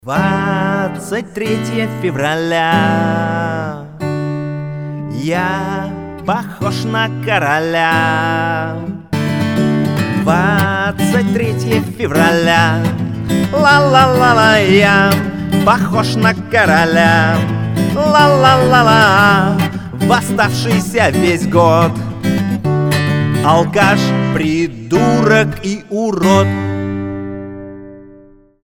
гитара
позитивные
русский рок